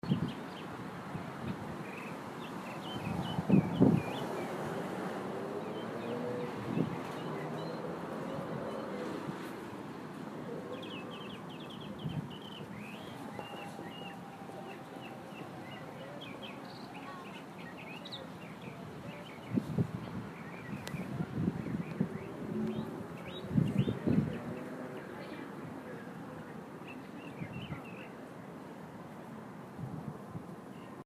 Field Recording-5
Outside the student center, 3-4
Bird chirping, wind, cars, people talking
Bird.mp3